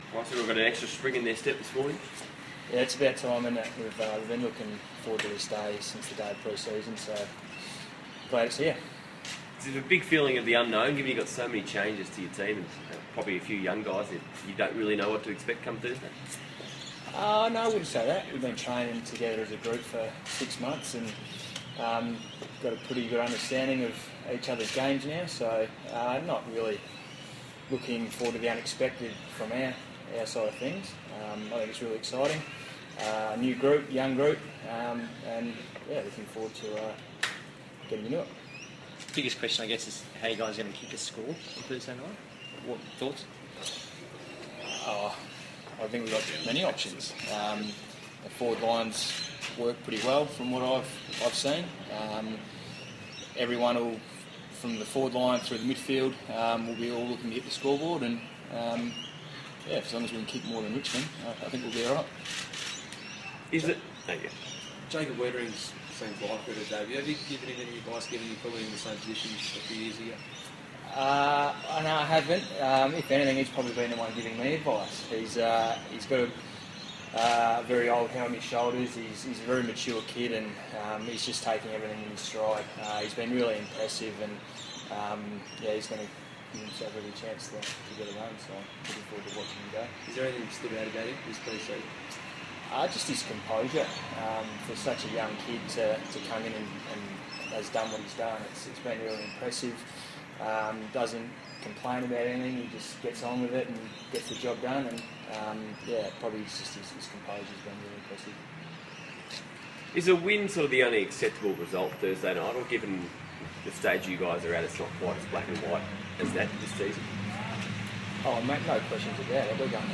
Bryce Gibbs press conference - March 21